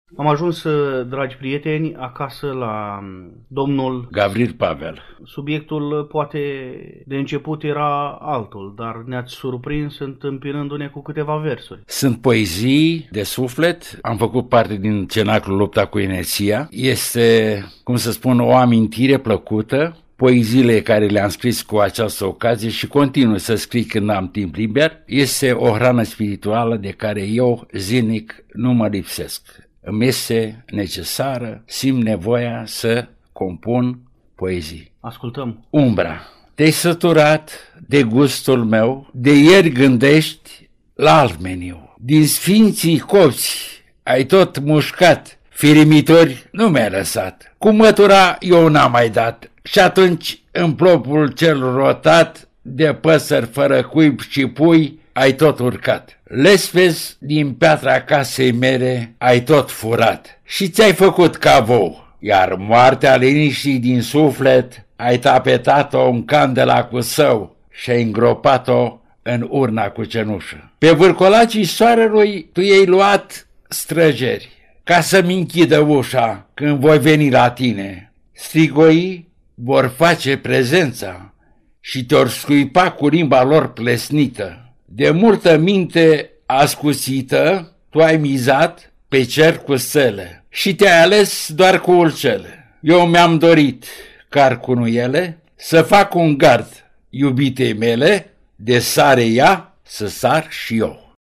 În timpul interviului, lutierul ne povestește cum s-a apropiat Domnia Sa de tainele instrumentelor cu coardă, vioara și viola.